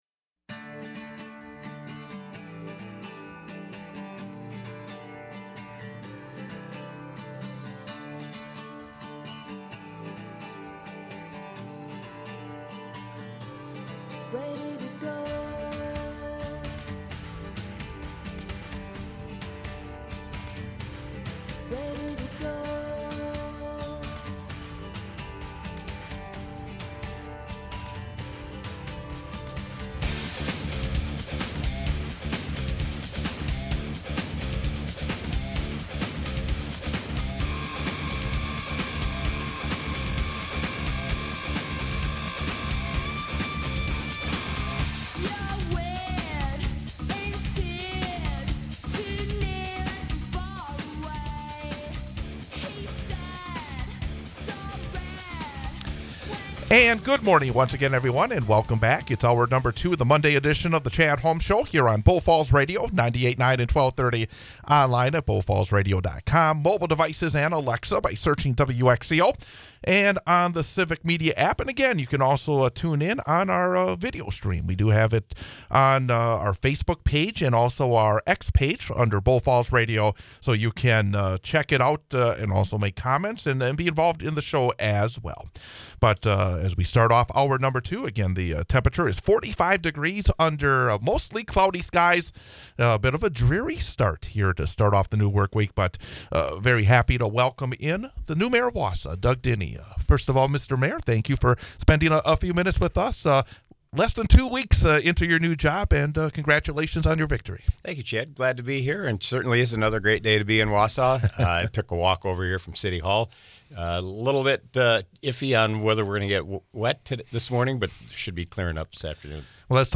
Guests: Doug Diny 4/29/2024 Listen Share We talk to new Wausau mayor Doug Diny on the transition and first two weeks in office plus issues of water, homelessness, the police, and economic development.